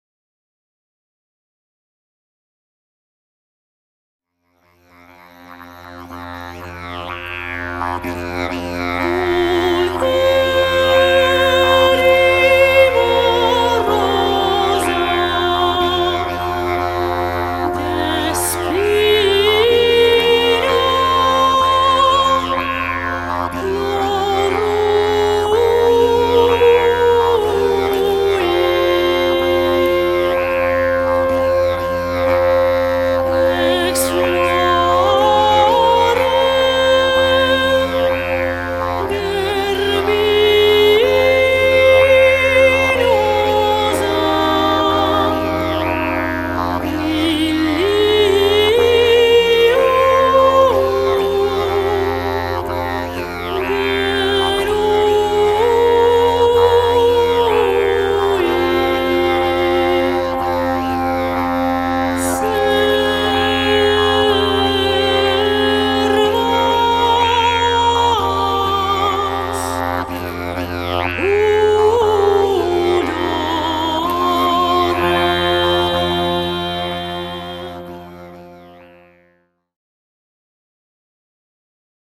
hörproben didgeridoo und gesang